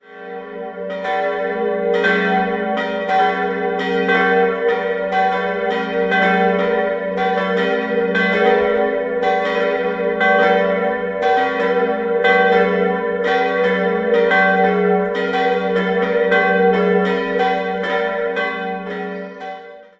Der prächtige Innenraum weist Stilelemente des ausgehenden Barock und des Klassizismus auf. 3-stimmiges Geläute: e'-fis'-ais' Alle Glocken wurden im Jahr 1691 von Johann Gordian Schelchshorn in Regensburg gegossen.